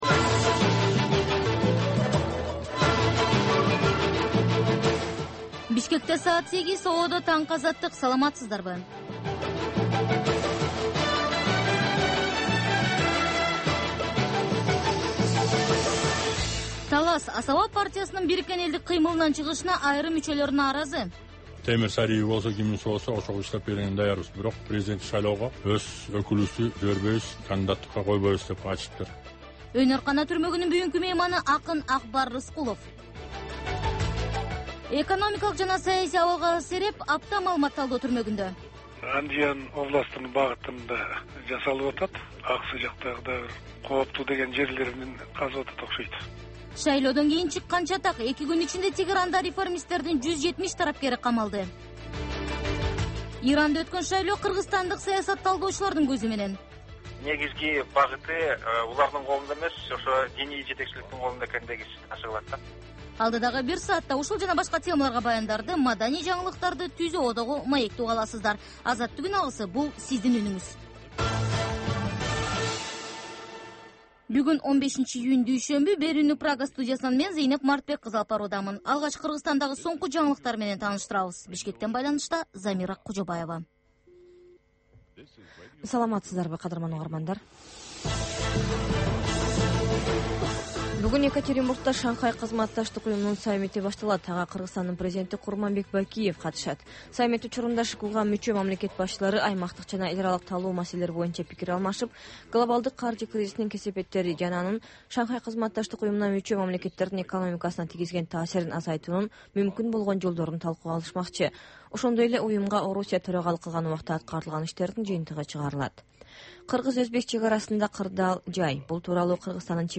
Бул таңкы үналгы берүү жергиликтүү жана эл аралык кабарлардан, ар кыл окуялар тууралуу репортаж, маек, тегерек үстөл баарлашуусу, талкуу, баян жана башка берүүлөрдөн турат. "Азаттык үналгысынын" бул таңкы берүүсү Бишкек убактысы боюнча саат 08:00ден 09:00га чейин обого чыгарылат.